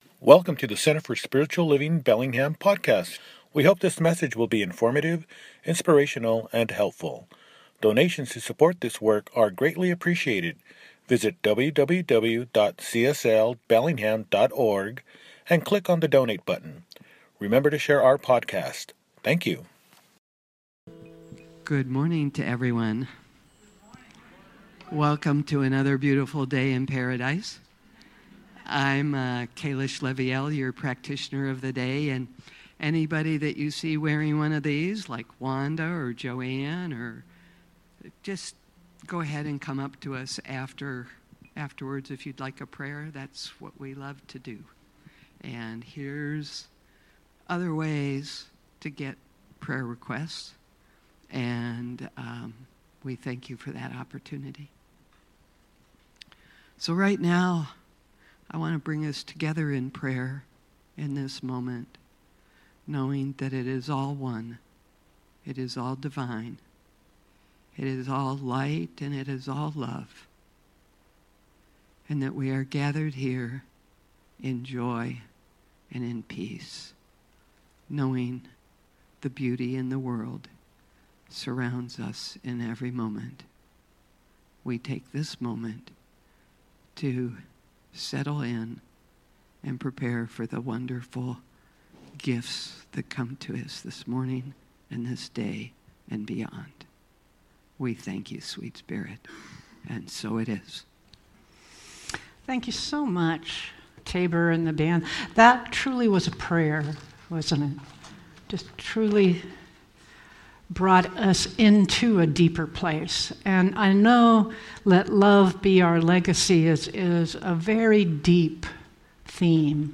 You Finish with a New Beginning! – Celebration Service
Sep 7, 2025 | Podcasts, Services